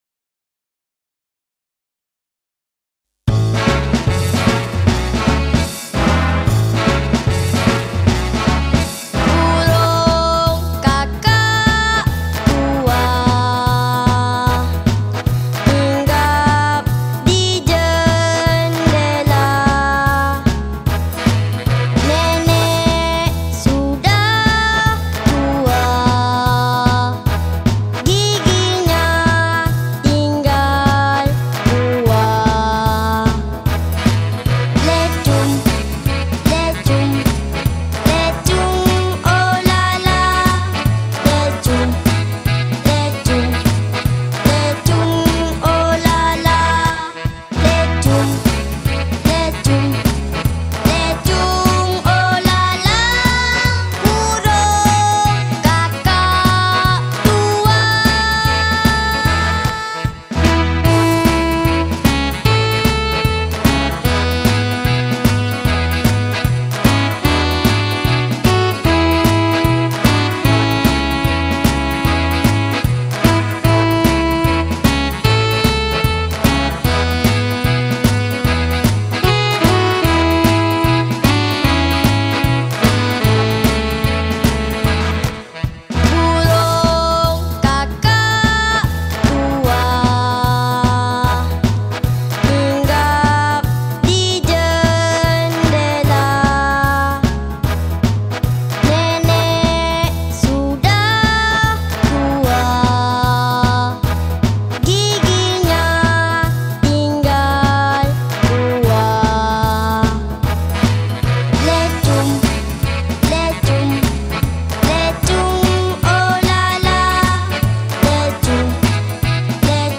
Nursery Ryhme